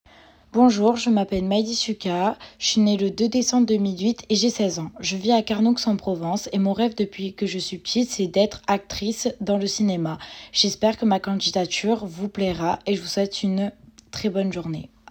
Présentation de ma voix
Comédienne
16 - 100 ans - Mezzo-soprano